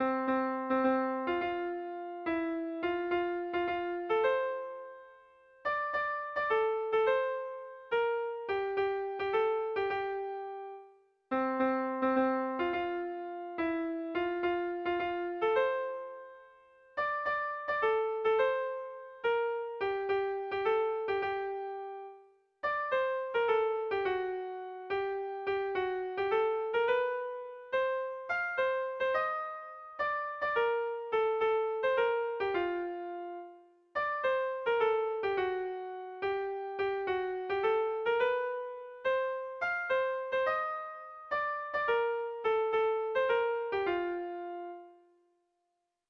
Kontakizunezkoa
Gipuzkoa < Euskal Herria
Zortziko txikia (hg) / Lau puntuko txikia (ip)
ABDE